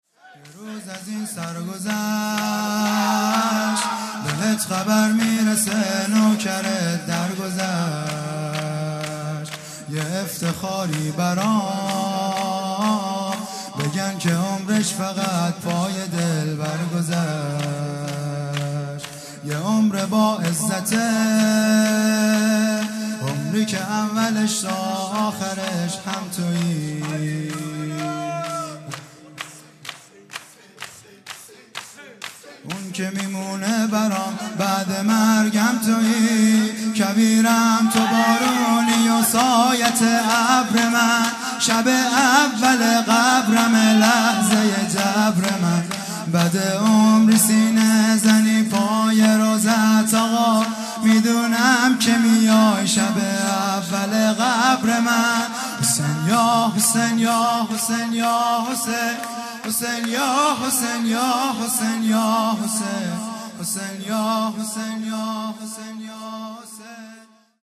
هیئت دانشجویی فاطمیون دانشگاه یزد
شور
شهادت حضرت زهرا (س) | ۲۴ بهمن ۱۳۹۵